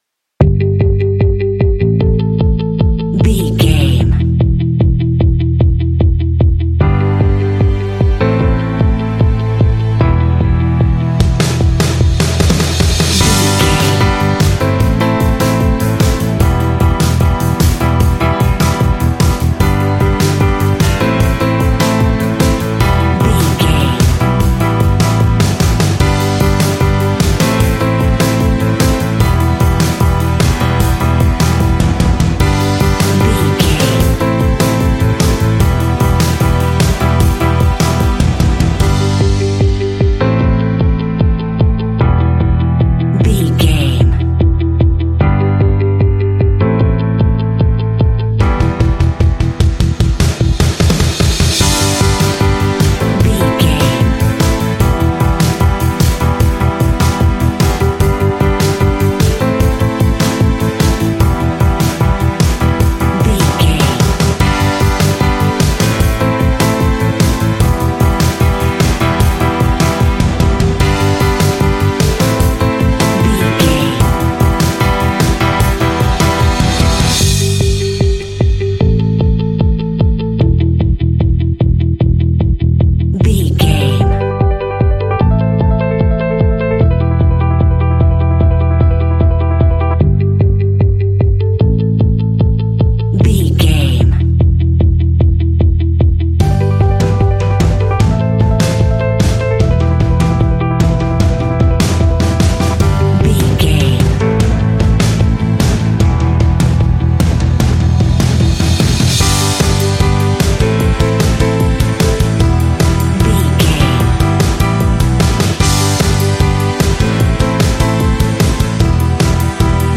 Epic / Action
Uplifting
Aeolian/Minor
driving
bright
hopeful
elegant
strings
bass guitar
electric guitar
piano
drums
indie
alternative rock